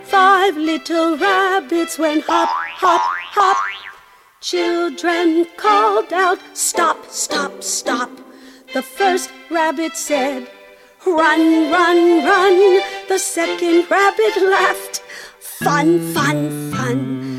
Genre: Children's Music.